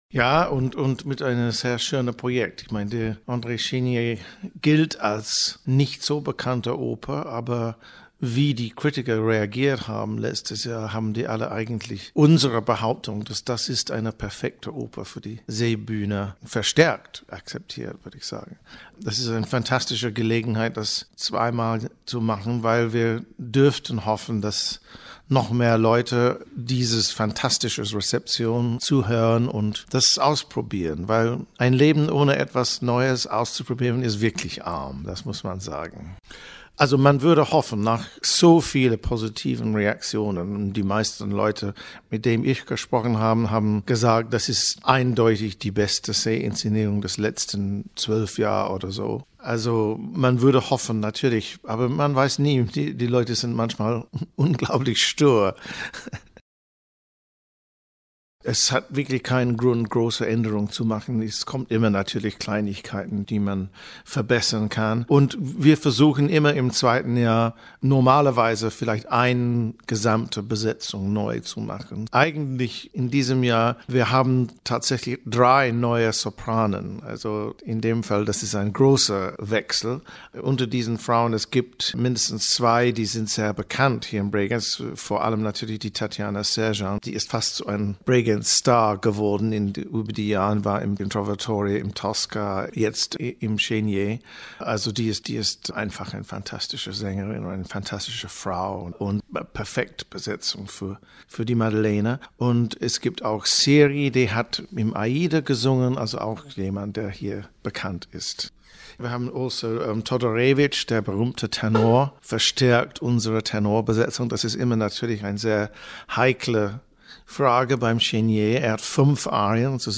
Interview mit David Pountney, Intendant der Bregenzer Festspiele